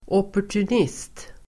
Ladda ner uttalet
opportunist substantiv, opportunist Uttal: [åpor_tun'is:t] Böjningar: opportunisten, opportunister Definition: person som alltför lätt anpassar sig efter gällande omständigheter Avledningar: opportunism (opportunism)